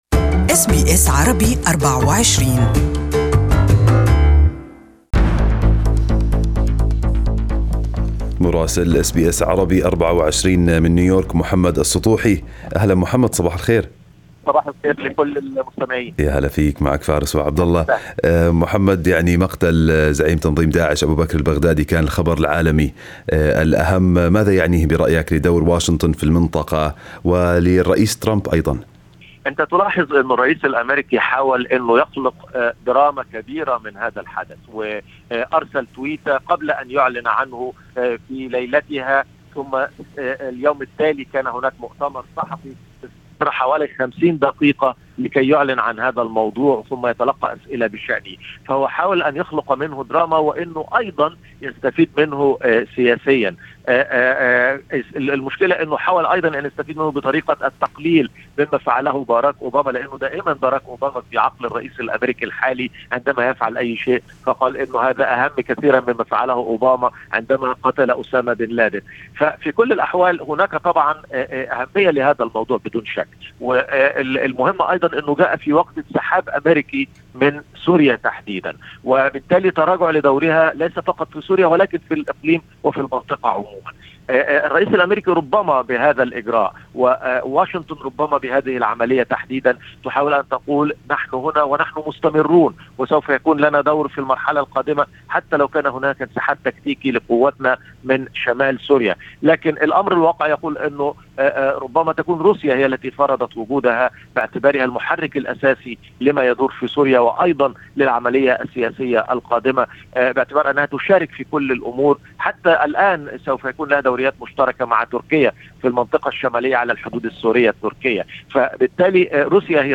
Listen to the full report from our correspondent from New York